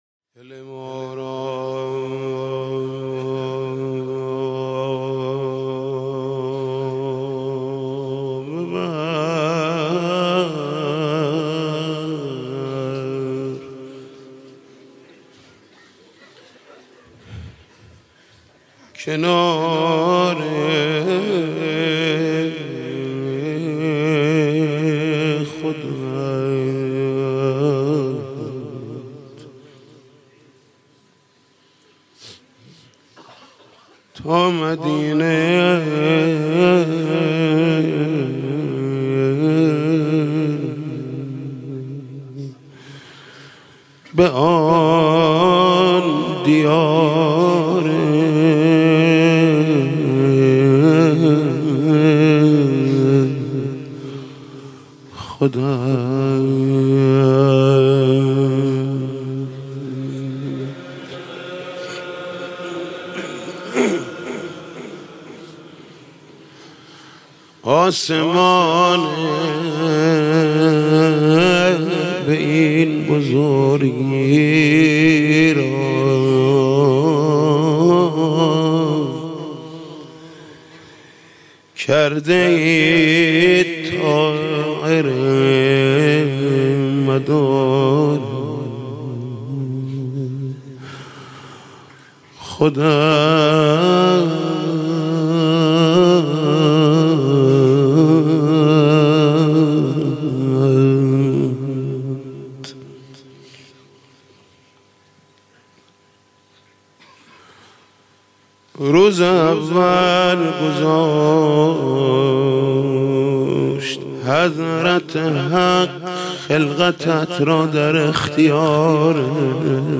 دانلود مداحی مدینه بود و کینه بود - دانلود ریمیکس و آهنگ جدید